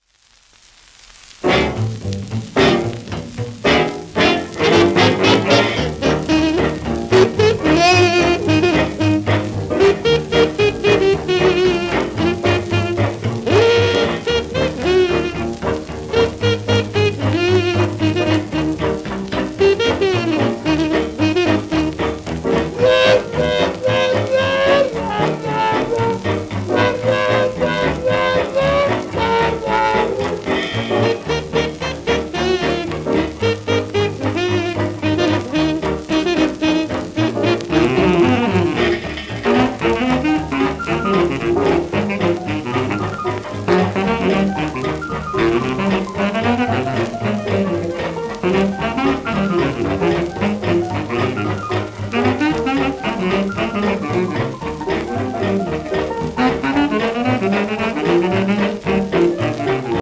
w/オーケストラ
1929年録音
シェルマン アートワークスのSPレコード